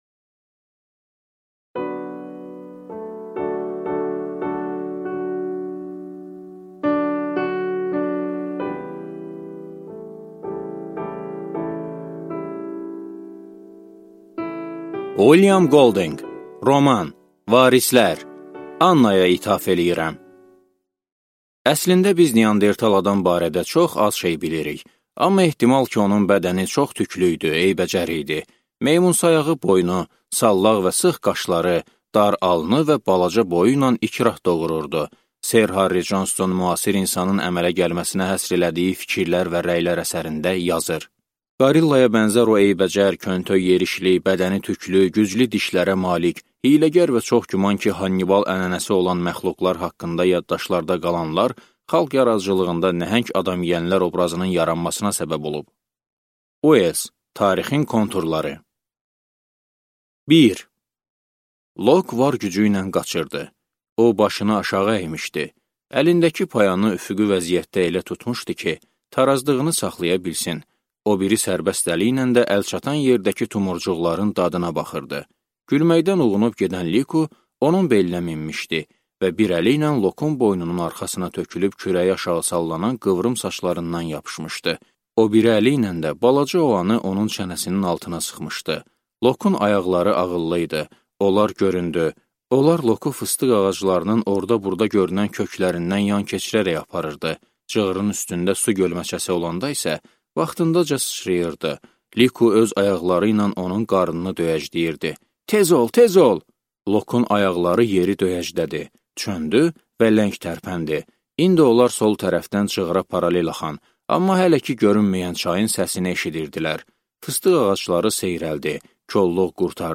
Аудиокнига Varislər | Библиотека аудиокниг